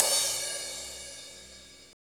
CYM MAX C0ER.wav